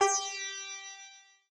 sitar_g.ogg